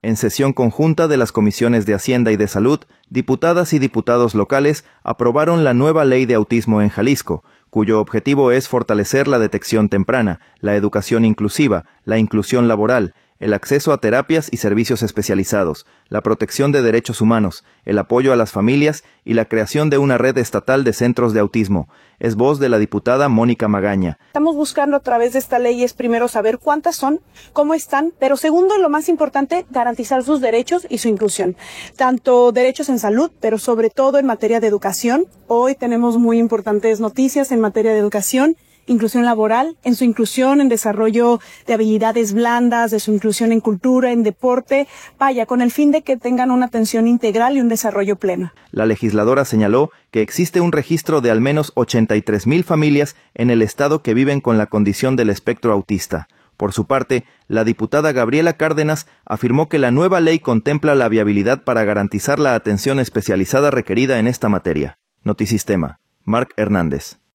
En sesión conjunta de las comisiones de Hacienda y de Salud, diputadas y diputados locales aprobaron la nueva Ley de Autismo en Jalisco, cuyo objetivo es fortalecer la detección temprana, la educación inclusiva, la inclusión laboral, el acceso a terapias y servicios especializados, la protección de derechos humanos, el apoyo a las familias y la creación de una Red Estatal de Centros de Autismo. Es voz de la diputada Mónica Magaña.